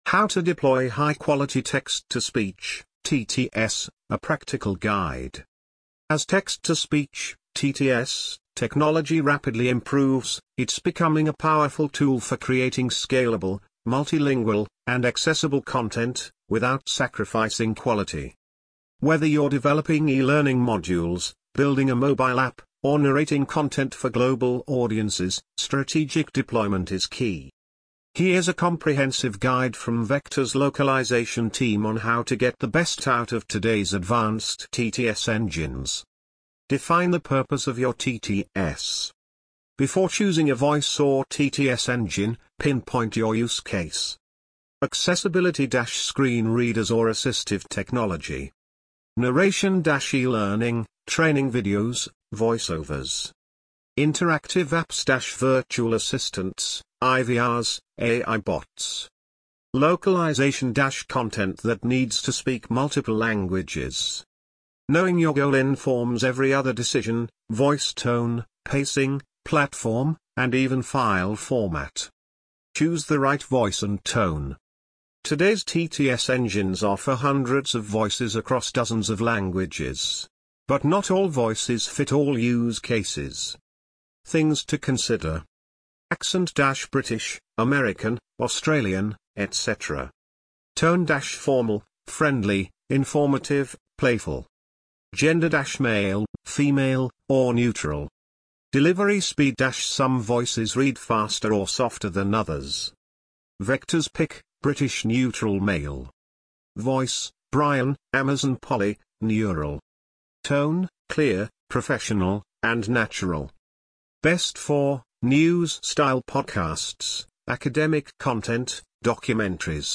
easytts_audio_How-to-Deploy-High-Quality-Text-to-Speech-TTS-A-Practical-Guide1.mp3